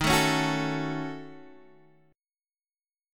D# Minor 7th Flat 5th